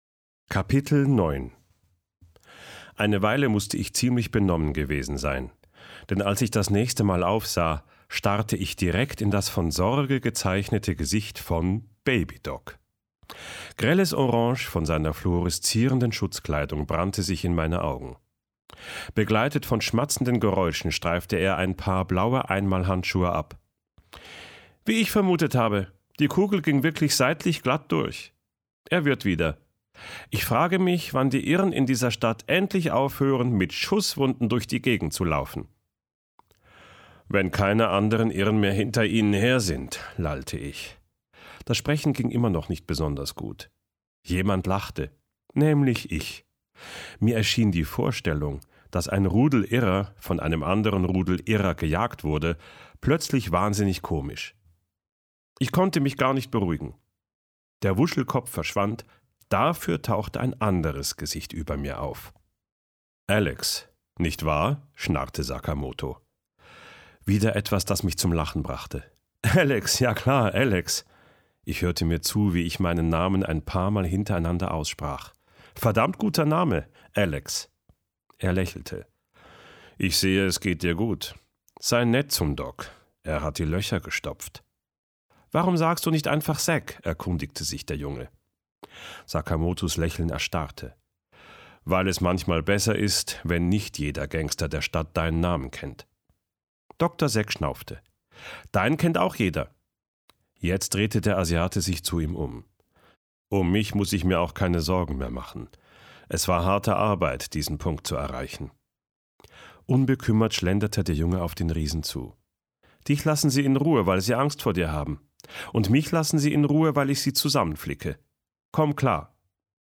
Hörbuch – 6,99 €